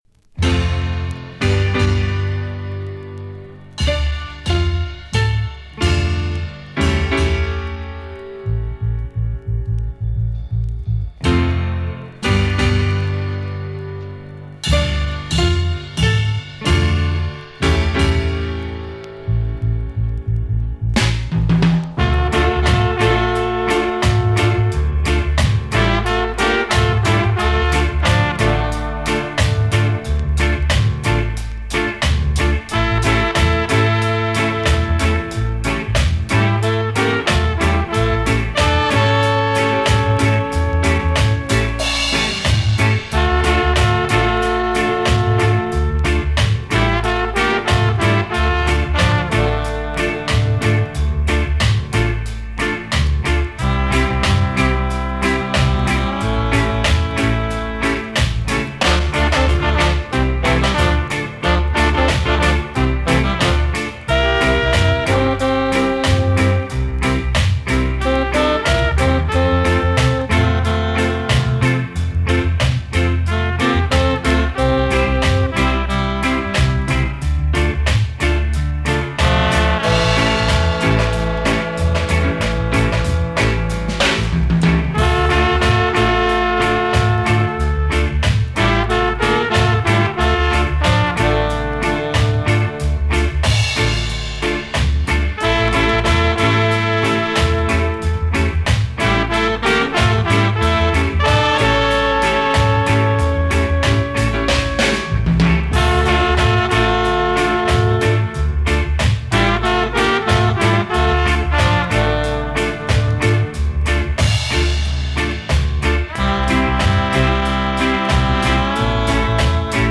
One turntable, one echo unit, one record